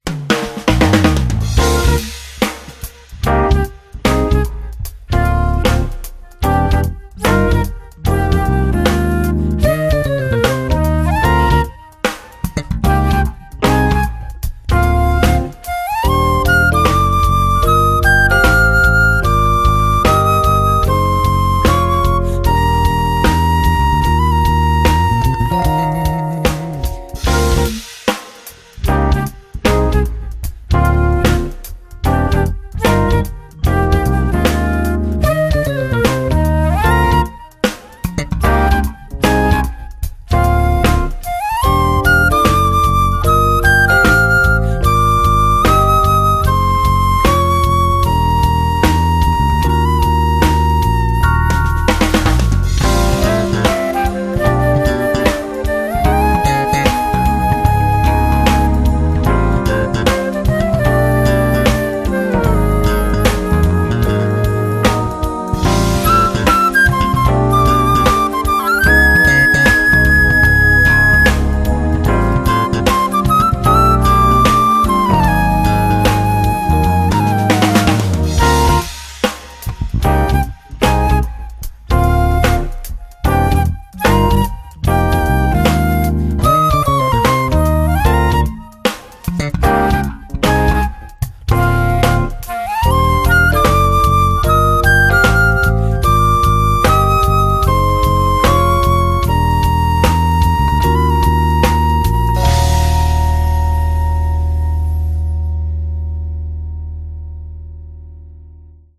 CD v súčasnom štýle groove-fusion-smooth-nu-...-jazz.
flauta, píšťalka, spev
klávesy
basgitara
bicie